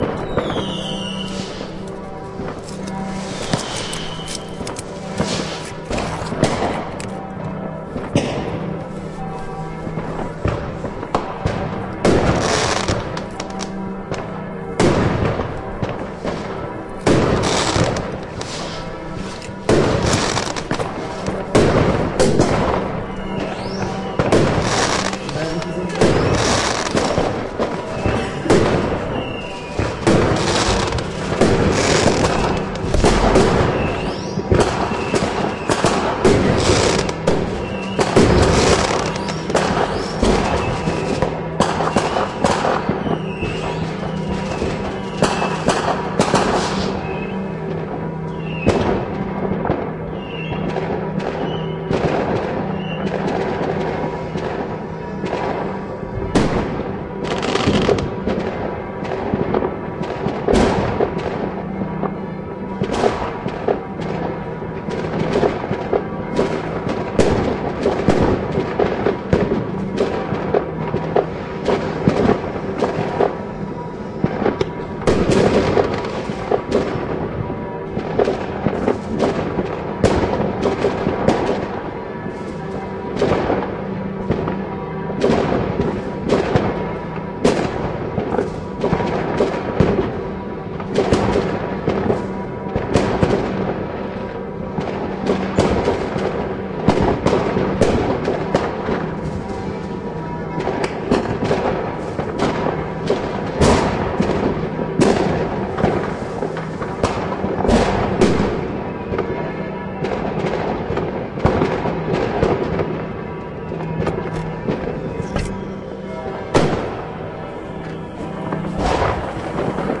描述：在2008/2009年新年前夕的午夜，打开香槟酒瓶。在1点56分，是香槟酒的软木塞，不是鞭炮！
Tag: 软木 香槟 香槟酒瓶塞 基督教 教堂 2009年 新的年前夕 钟声 鞭炮 鞭炮 烟花 新年